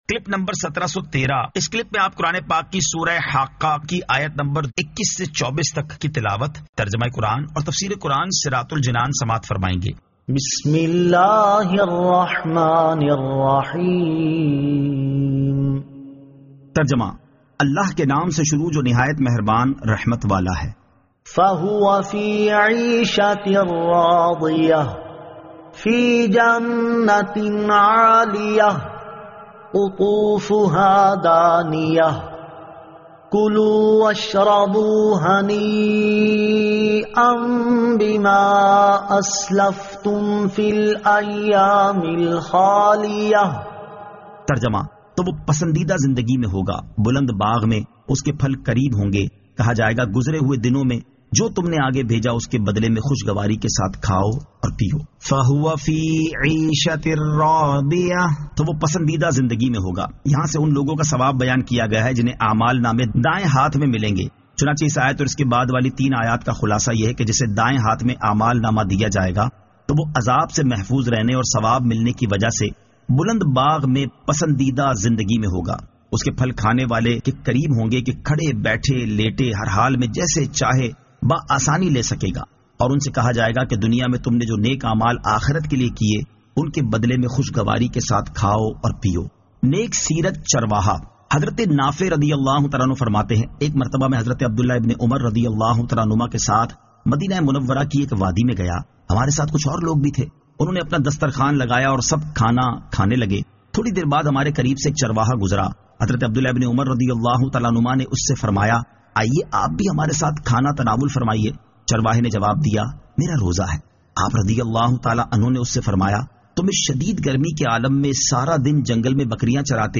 Surah Al-Haqqah 21 To 24 Tilawat , Tarjama , Tafseer